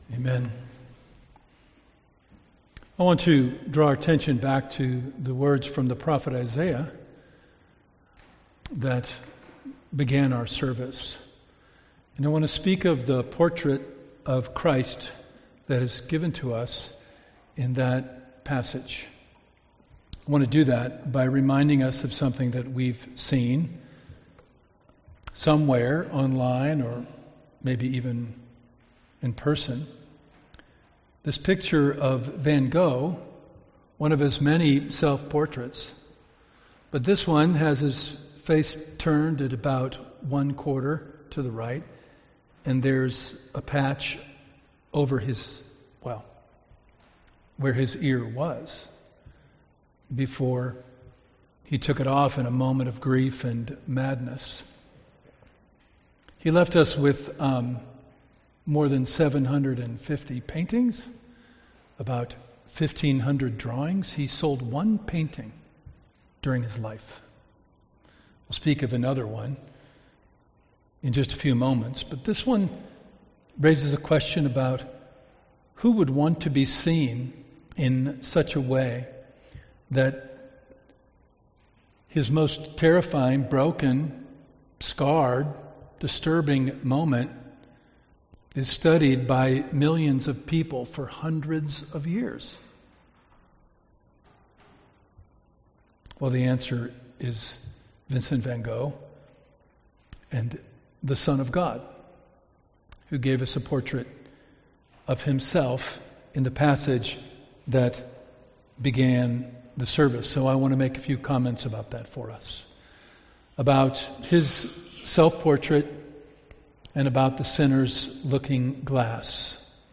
Good_Friday_2026_Homily.mp3